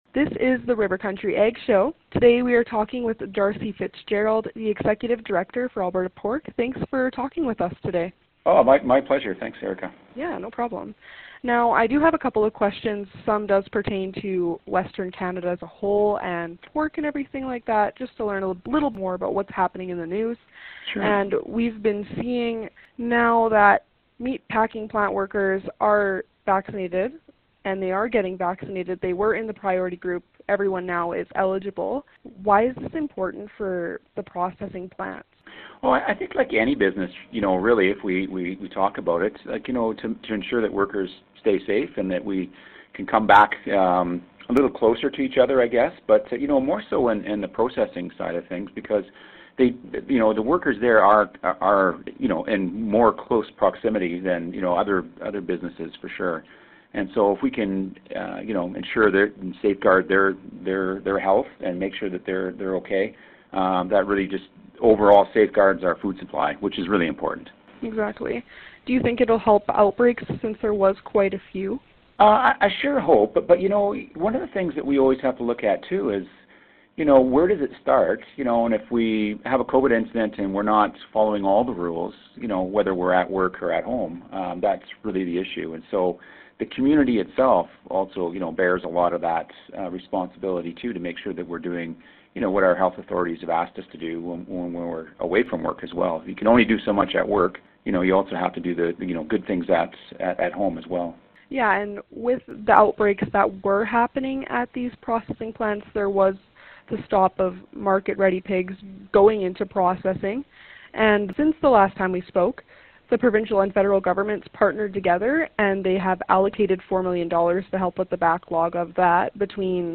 Listen to our conversation down below and to our Ag Show every weekday during the noon hour! https